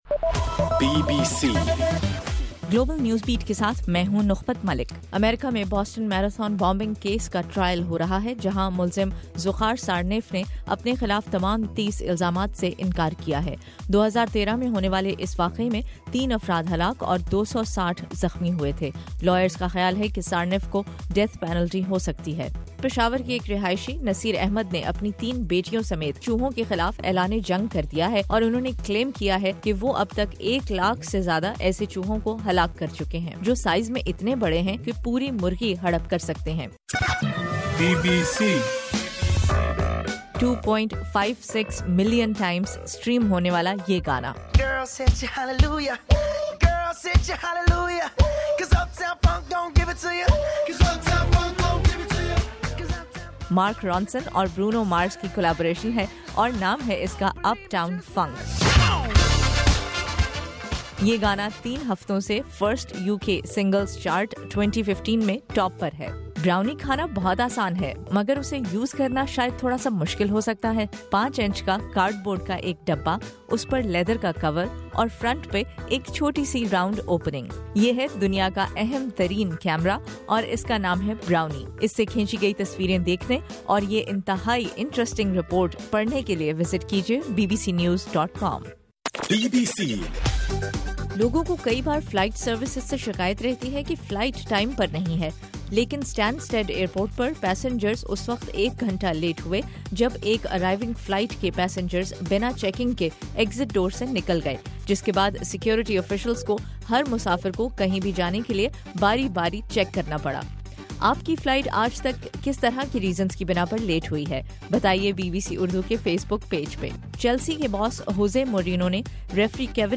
جنوری 5: رات 12 بجے کا گلوبل نیوز بیٹ بُلیٹن